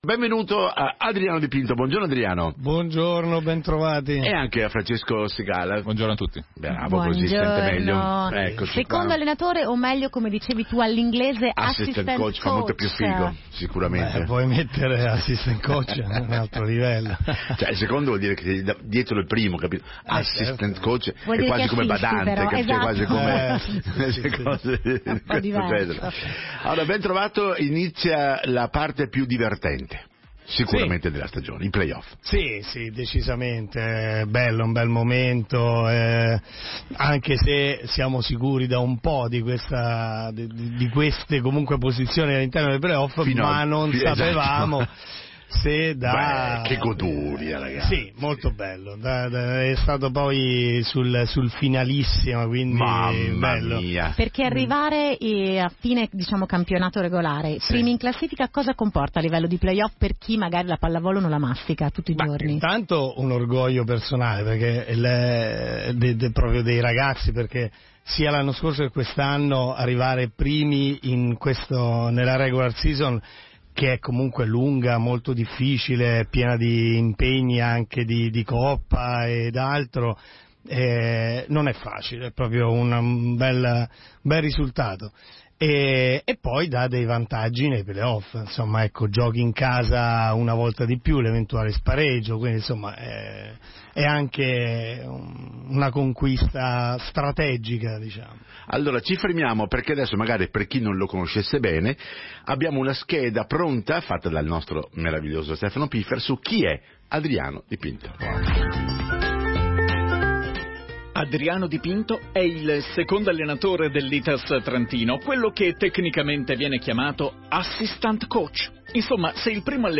Mp3 interview